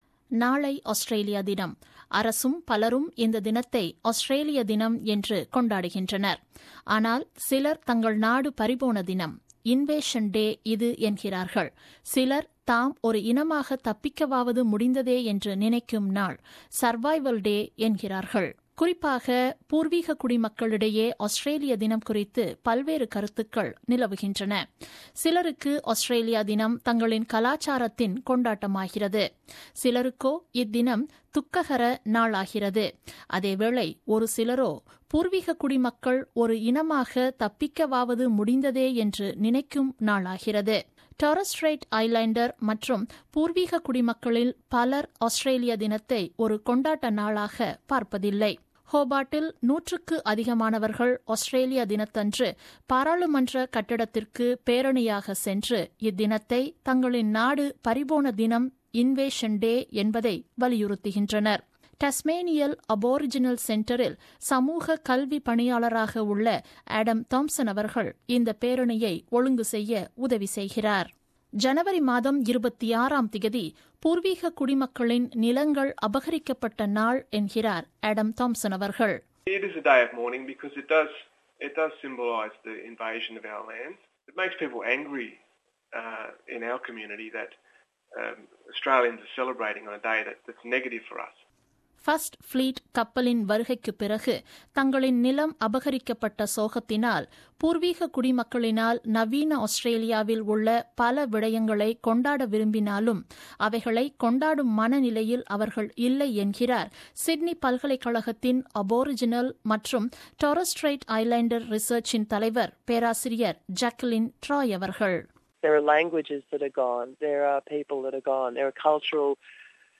ஆனால் சிலர் தங்கள் நாடு பறிபோன தினம் Invasion Day இது என்கின்றார்கள். சிலர் தாம் ஒரு இனமாக தப்பிக்கவாவது முடிந்ததே என்று நினைக்கும் நாள் Survival Day என்கின்றார்கள். இது குறித்த ஒரு விவரணம்.